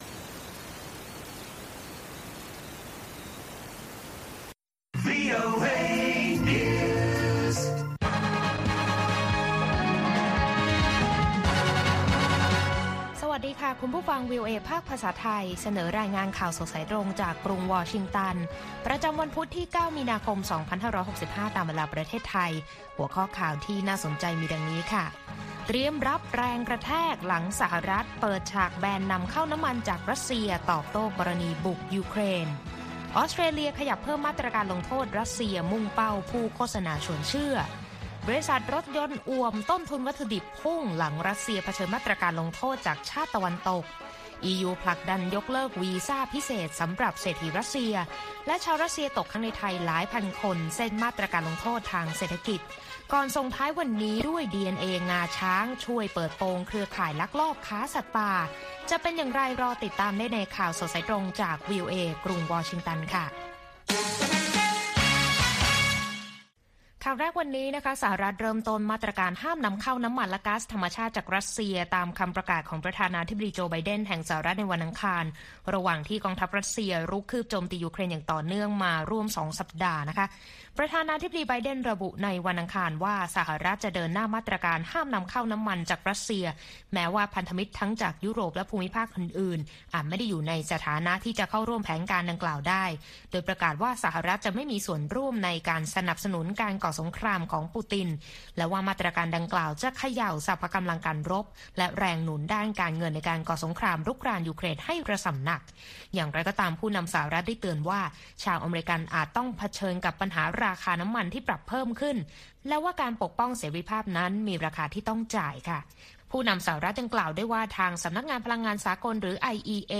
ข่าวสดสายตรงจากวีโอเอ ภาคภาษาไทย วันพุธ ที่ 9 มีนาคม 2565